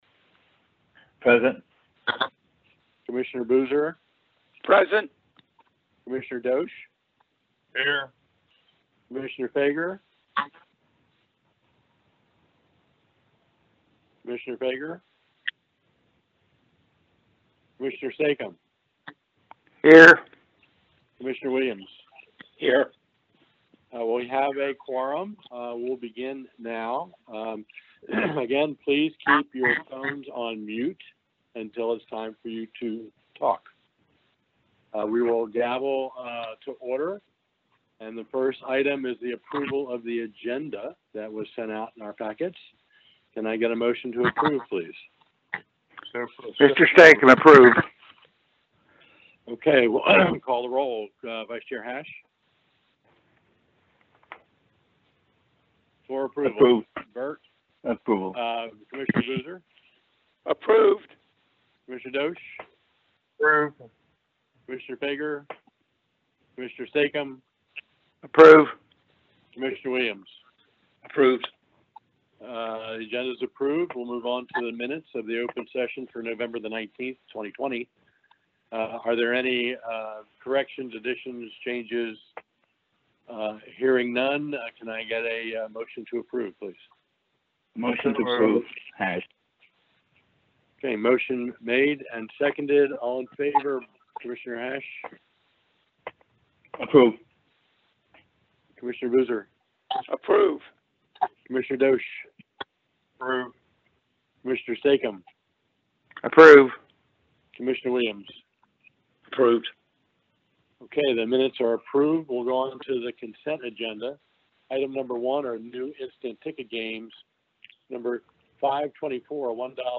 The next Maryland Lottery and Gaming Commission meeting will be held on Thursday, December 17, 2020, at 10:00 a.m. Due to COVID-19, the meeting was held via teleconference.